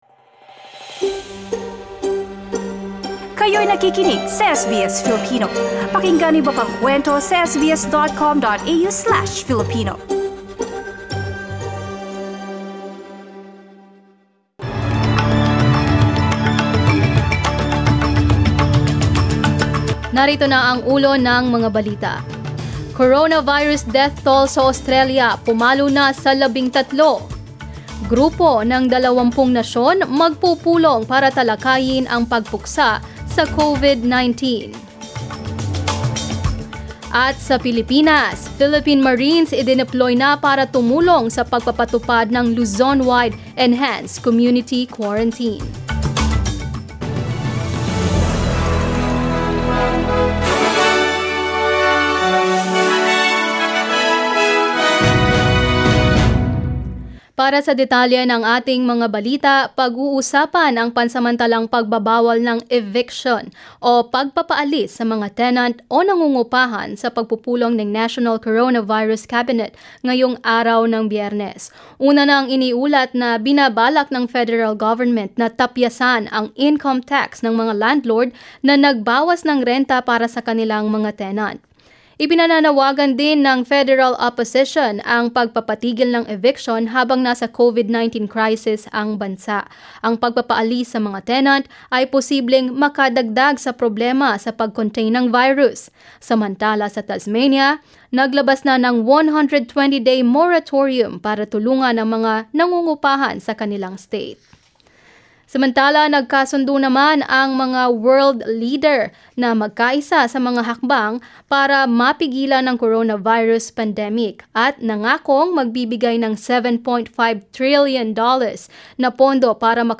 SBS News in Filipino, Friday 27 March